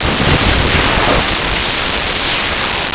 Laser2
laser2.wav